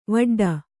♪ vaḍḍa